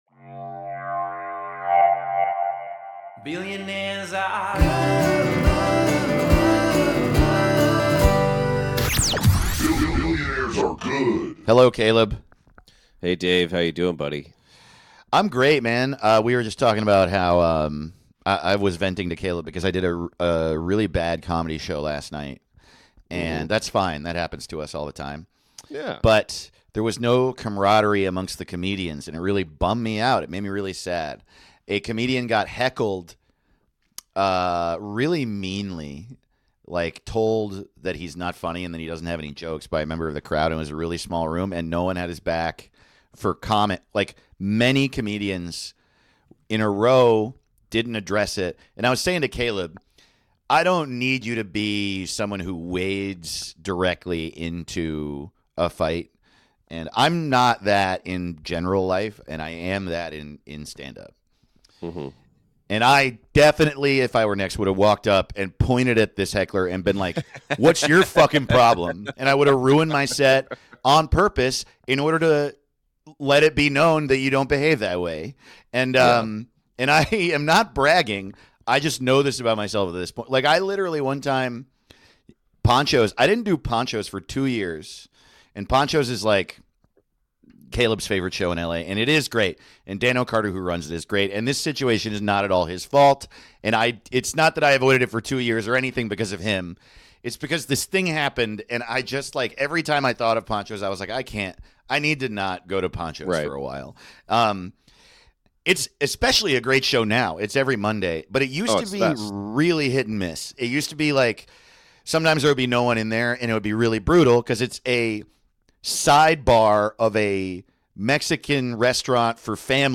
The show is a laugh-heavy romp through the darkness of capitalism that doesn't take itself seriously at all, rife with segments and tangents and rock radio stingersn. Money is bad and billionaires are good and we all need to lay down.